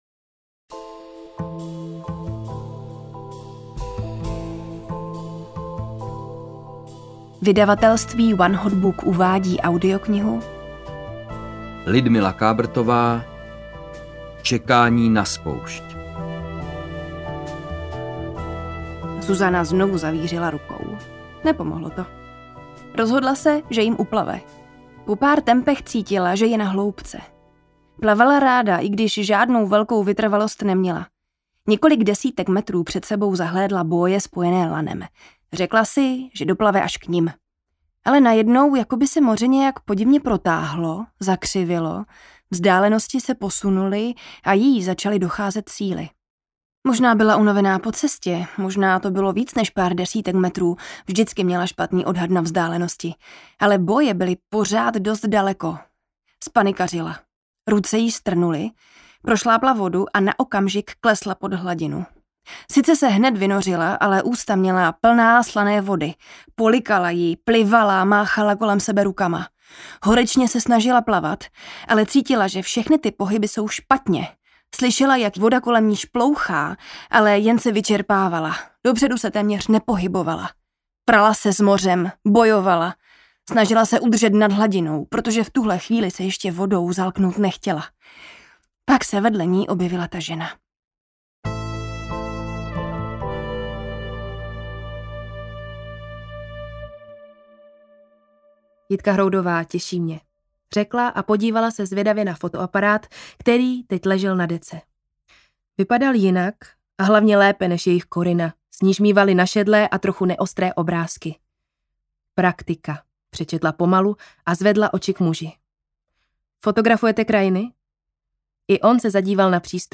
Čekání na spoušť audiokniha
Ukázka z knihy